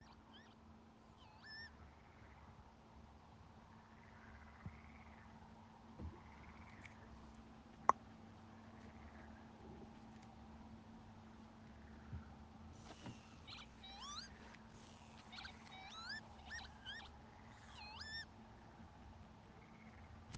Northern Lapwing, Vanellus vanellus
StatusSinging male in breeding season